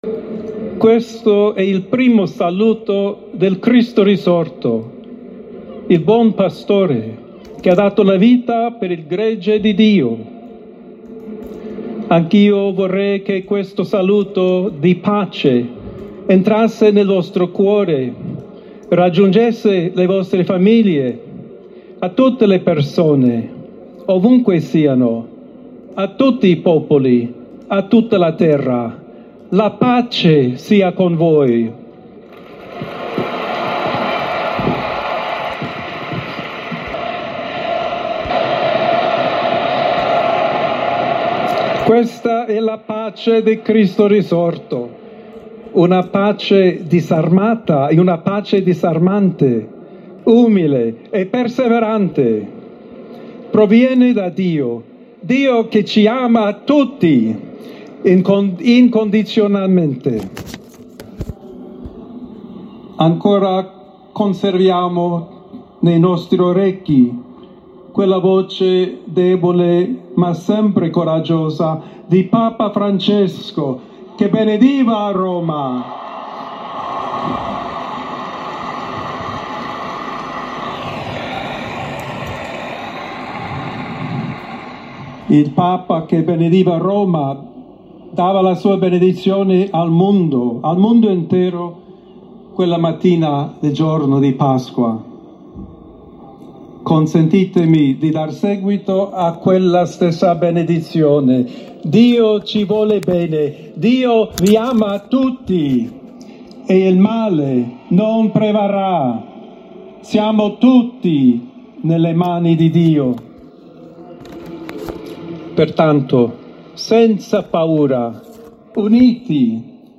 Il Pontefice è apparso in una Piazza San Pietro piena con circa 100.000 fedeli e, visibilmente commosso, ha salutato i fedeli con la mano.
Il primo discorso del Papa e la benedizione | Audio